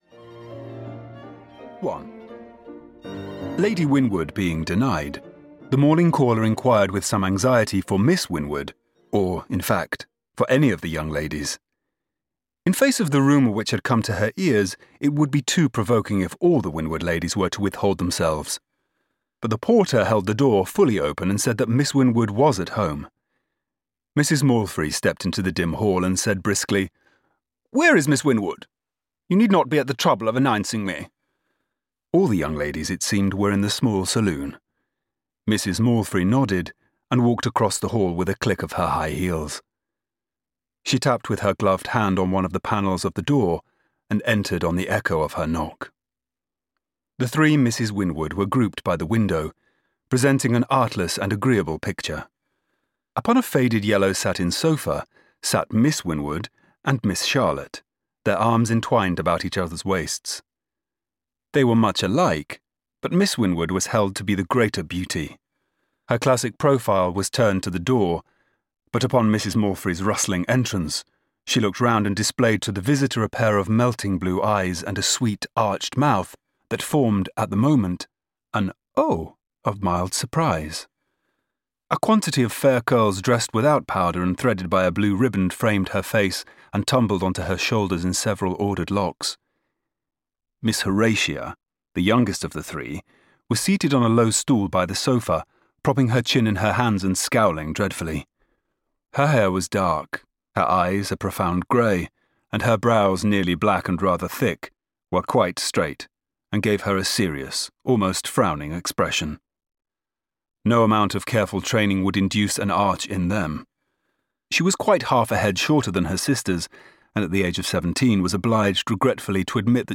Audio kniha
Ukázka z knihy
• InterpretRichard Armitage